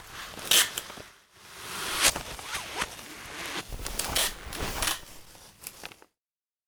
bandage_02.ogg